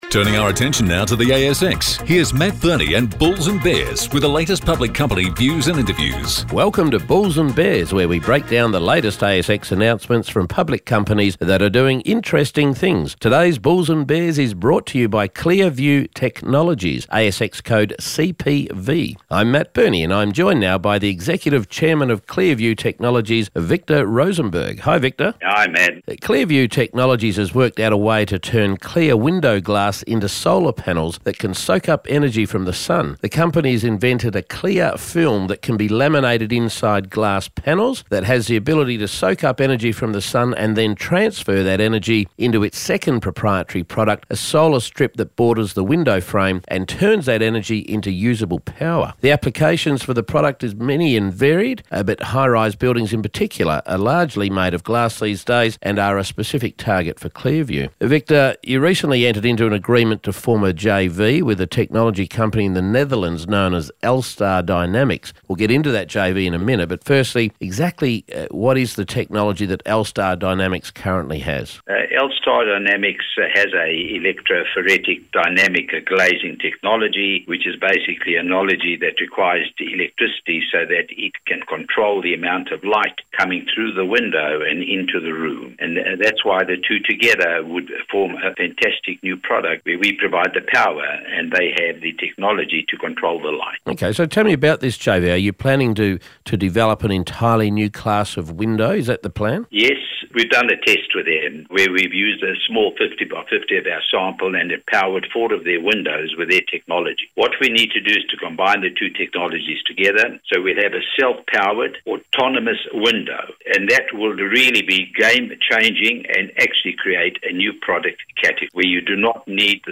Smart solar glass: an interview